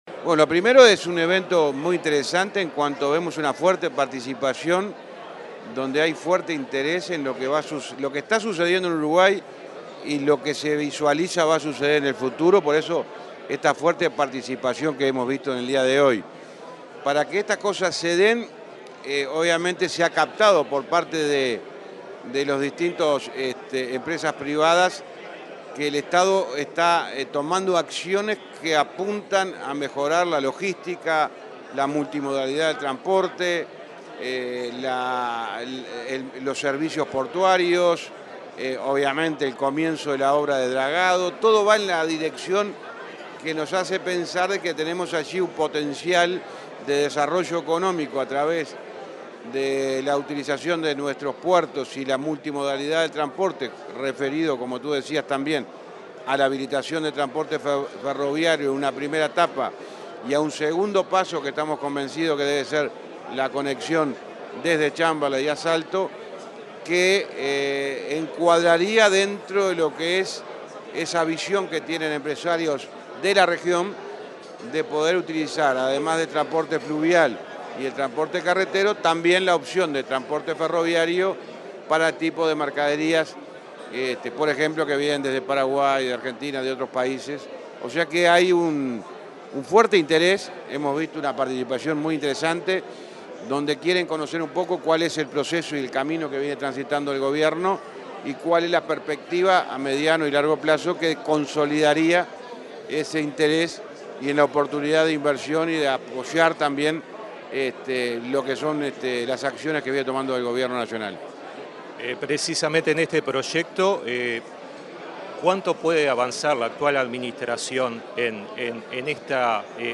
Declaraciones del ministro de Transporte, José Luis Falero
El ministro de Transporte, José Luis Falero, dialogó con la prensa, luego de participar en el Encuentro de Protagonistas: Uruguay, Negocios y